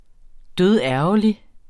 Udtale [ ˈdøðˈæɐ̯wʌli ]